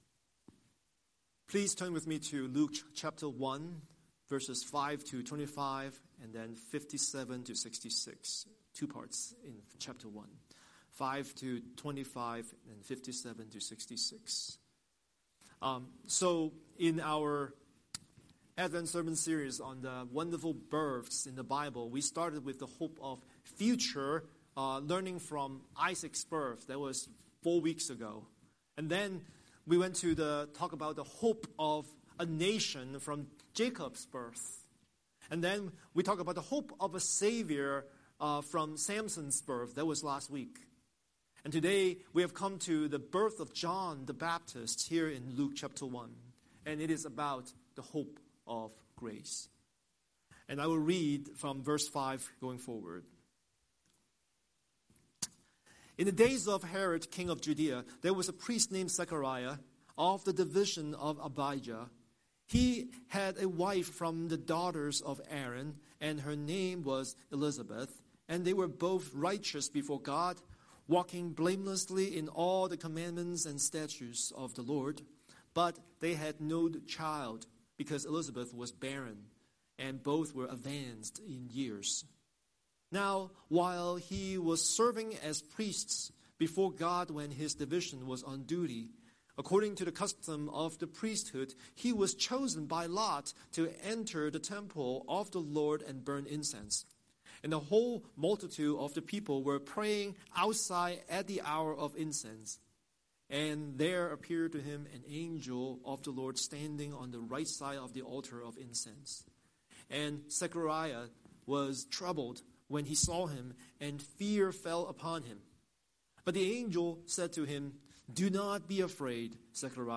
Scripture: Luke 1:5–25, 57-66 Series: Sunday Sermon